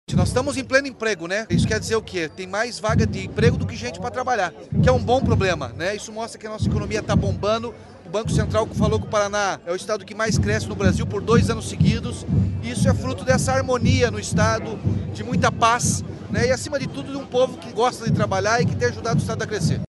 Sonora do governador Ratinho Junior sobre menor taxa de desemprego da história para o 2º trimestre